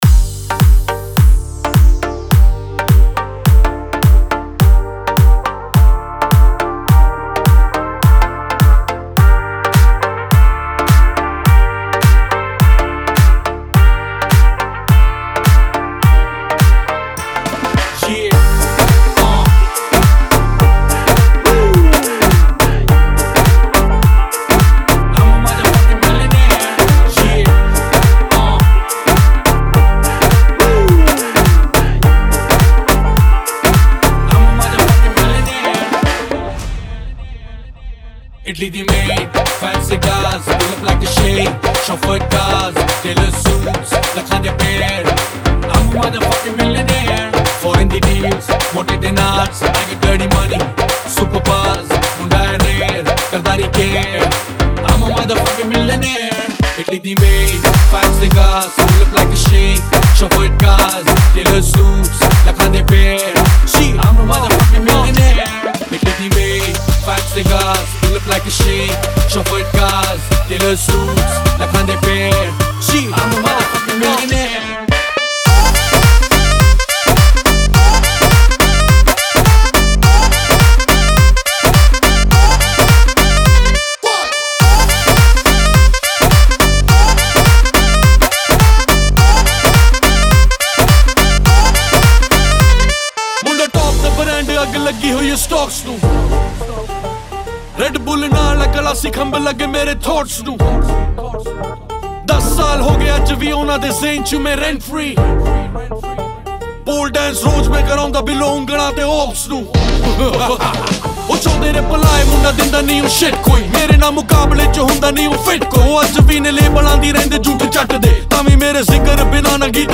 Desi Club Mix